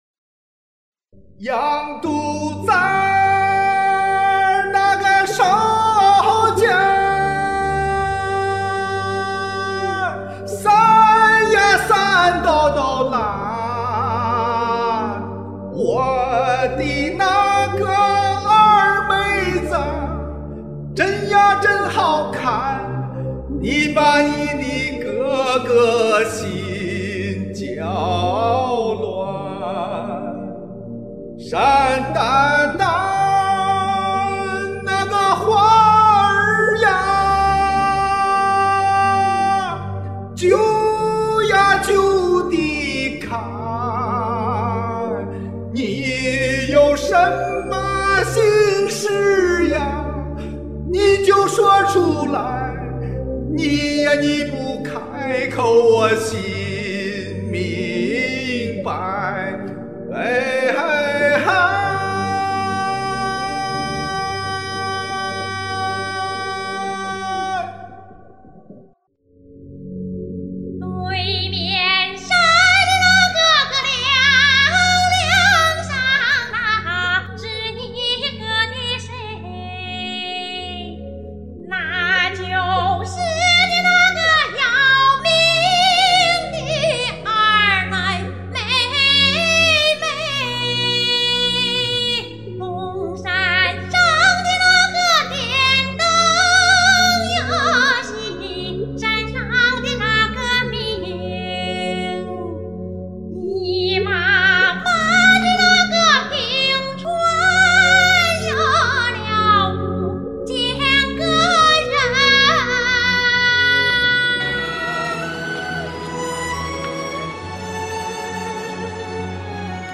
遗憾找不到伴奏，前两首勉强凑个消音伴奏，而对唱那首原唱也没伴奏，我们也只好清唱了；但愿听起来好像还自然。